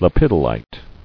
[le·pid·o·lite]